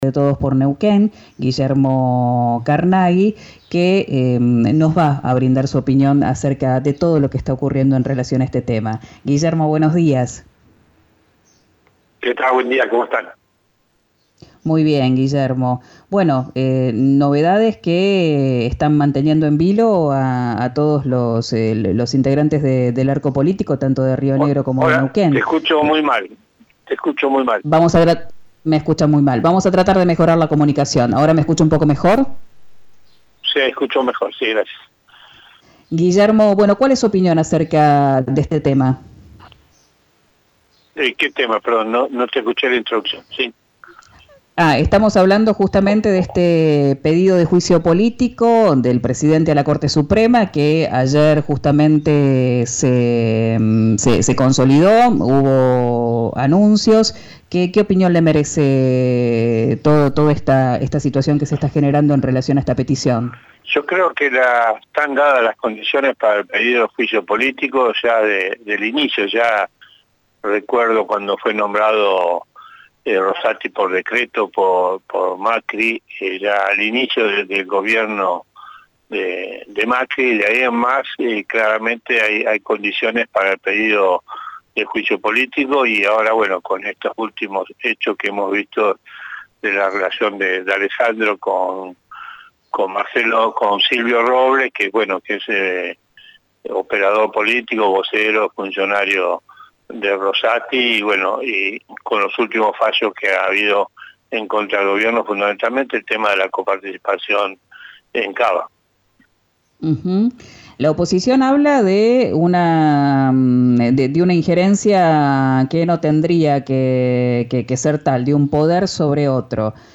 En diálogo con "Quién dijo verano" por RÍO NEGRO RADIO, el diputado del Frente de Todos aseguró que están demostrados los vínculos entre la corte Suprema de Justicia y funcionarios de la Ciudad de Buenos Aires.
Escuchá al diputado nacional por Neuquén del Frente de Todos, Guillermo Carnaghi, en “Quién dijo verano”, por RÍO NEGRO RADIO: